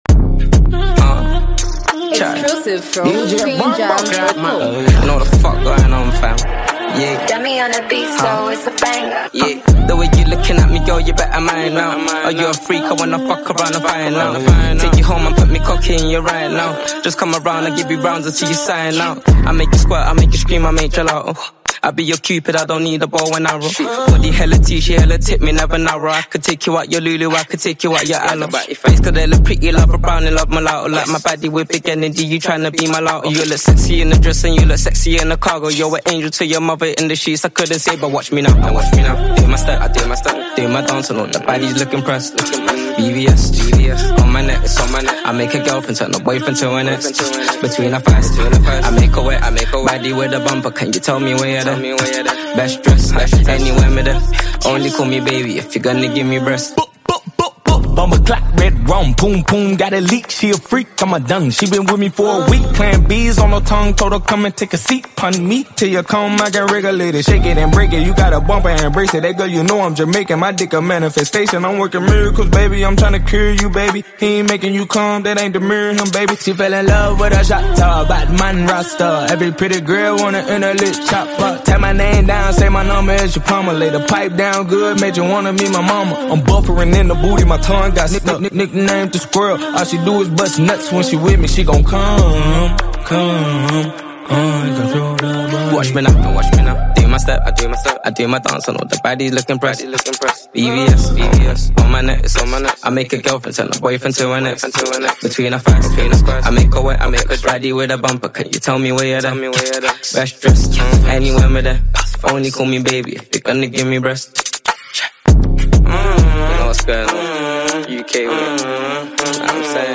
American hip-hop style
UK drill